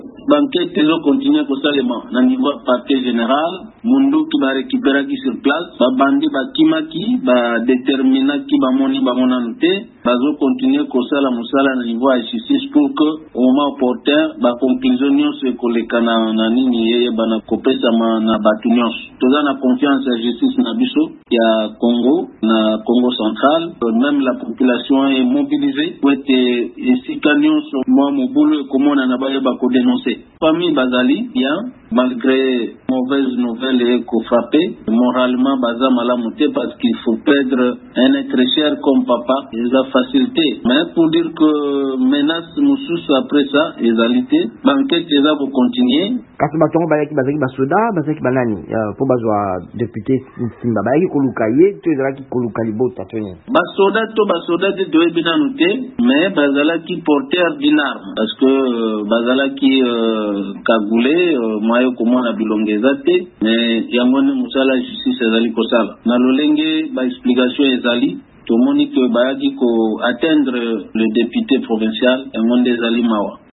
Nsima na kozwa monduki motikamaki na bisika ya bobomi mokeli mobeko ya province ya Kongo central Alebrt Nsimba, misala mya bosembo mizala kokoba bolukiluki. VOA Lingala ebengaki ministre provincial ya mambi ma bosembo, Edouard Samba.